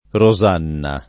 Rosanna
Rosanna [ ro @# nna ] pers. f.